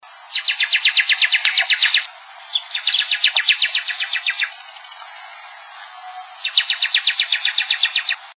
Angú (Donacobius atricapilla)
Nombre en inglés: Black-capped Donacobius
Provincia / Departamento: Corrientes
Localización detallada: Estancia San Juan Poriahú
Condición: Silvestre
Certeza: Vocalización Grabada